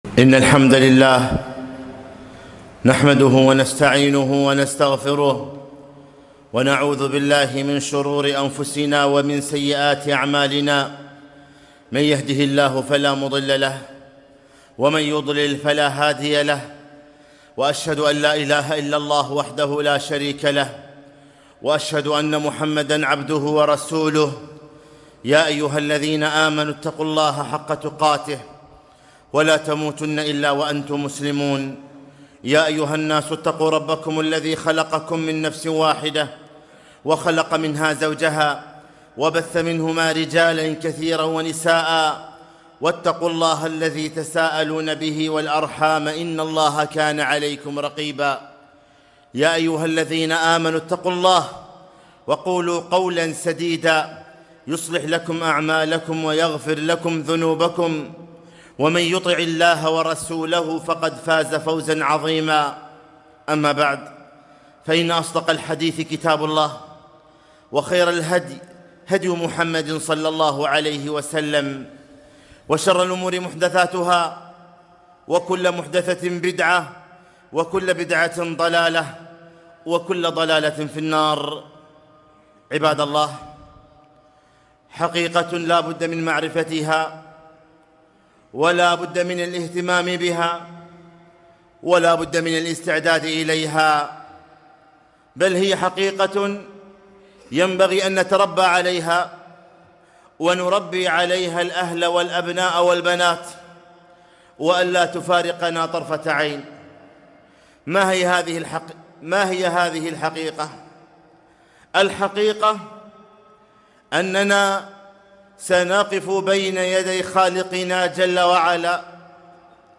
خطبة - وقفوهم إنهم مسئولون